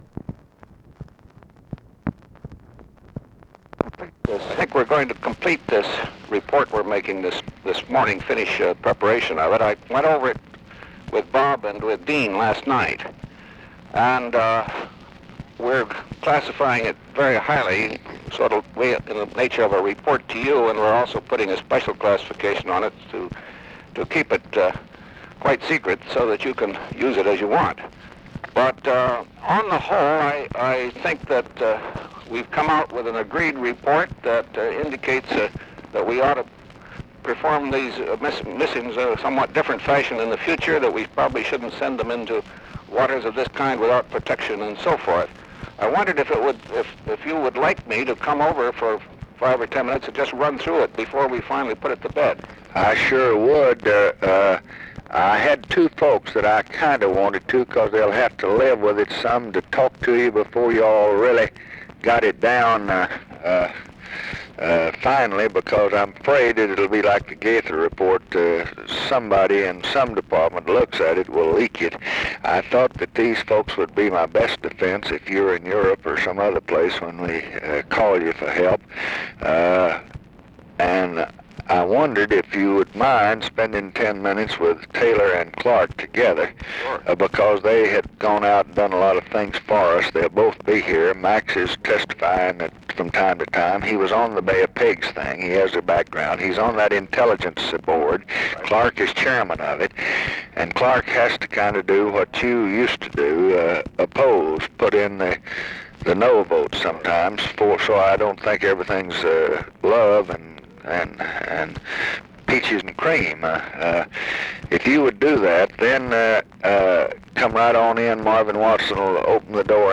Conversation with GEORGE BALL, February 7, 1968
Secret White House Tapes